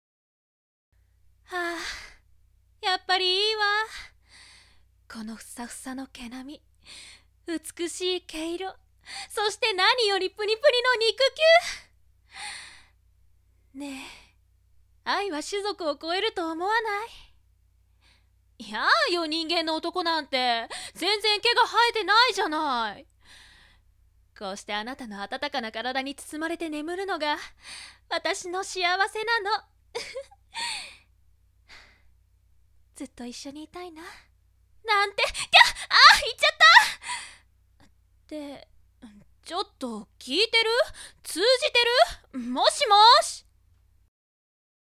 管理人２人のサンプルボイスが置いてあります。